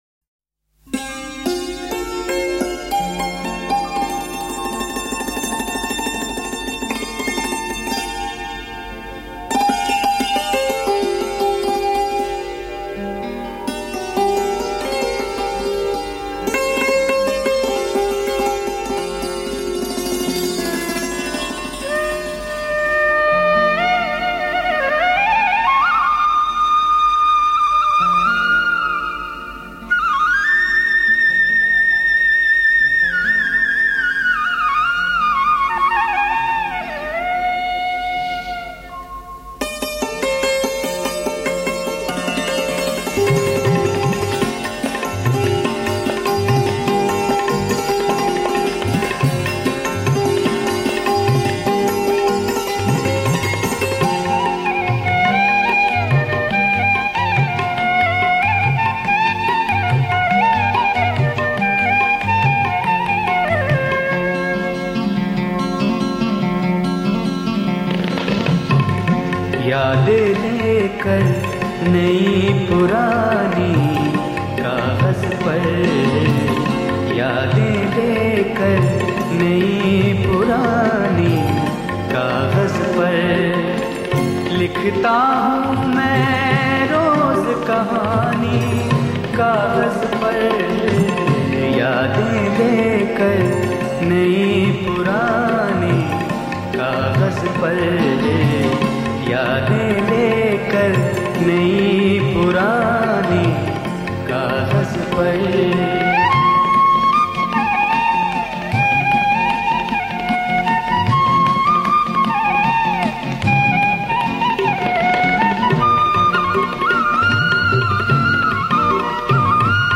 Ghazals
Live